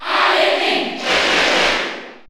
Crowd cheers (SSBU)
Link_&_Toon_Link_Cheer_French_NTSC_SSBU.ogg